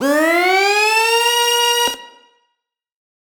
Index of /musicradar/future-rave-samples/Siren-Horn Type Hits/Ramp Up
FR_SirHornB[up]-C.wav